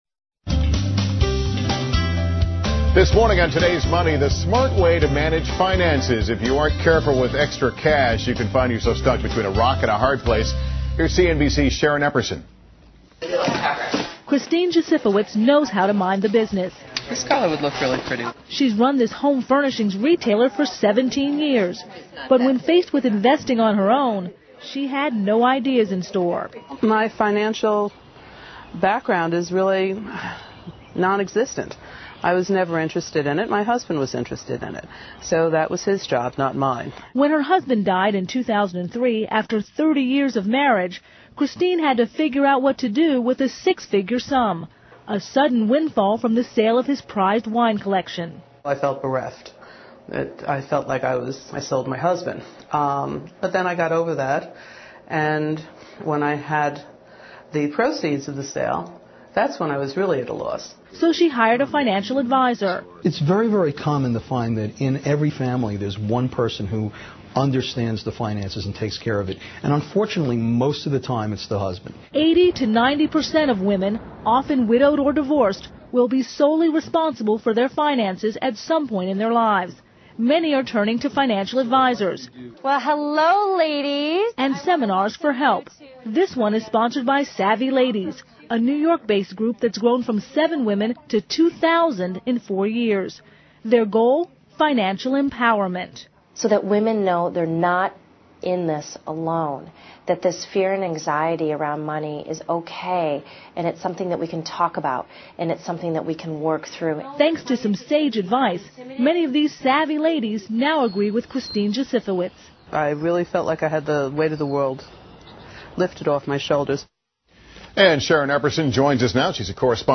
访谈录 Interview 2007-05-15&17, 金钱在人际关系中的力量 听力文件下载—在线英语听力室